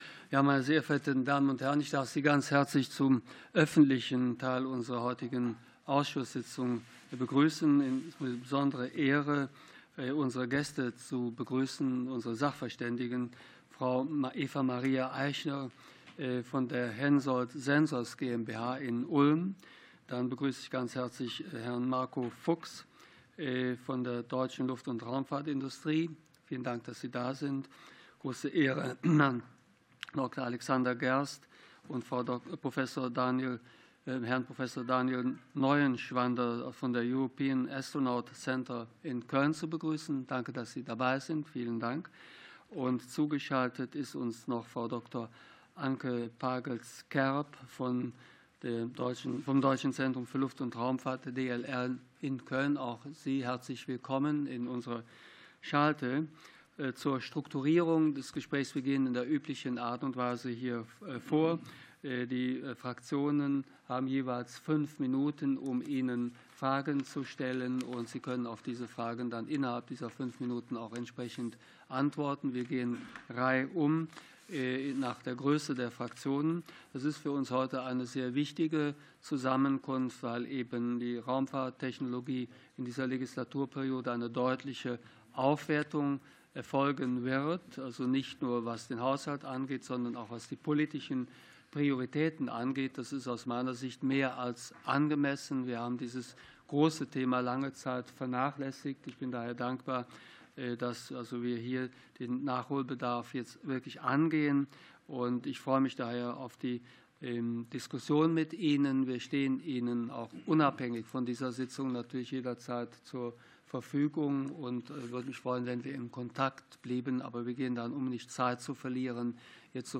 Fachgespräch zur ESA-Ministerratskonferenz 2025 ~ Ausschusssitzungen - Audio Podcasts Podcast
Ausschuss für Forschung, Technologie und Raumfahrt